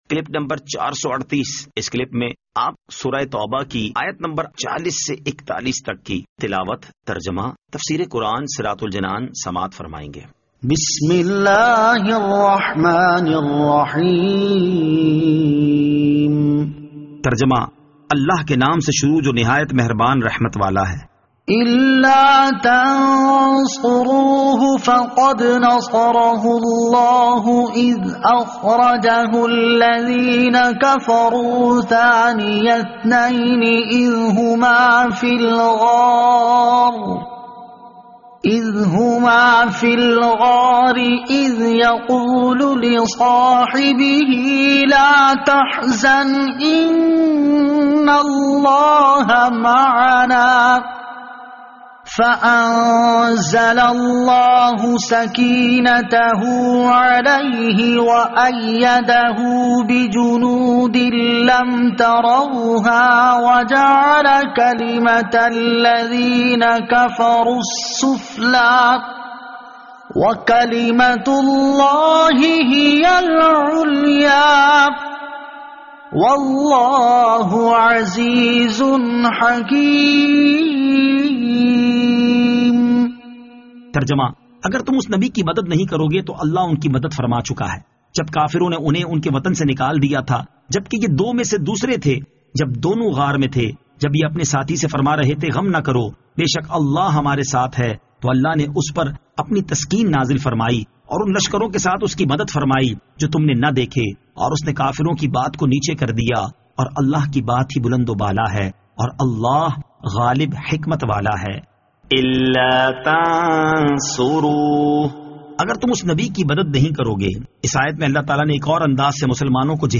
Surah At-Tawbah Ayat 40 To 41 Tilawat , Tarjama , Tafseer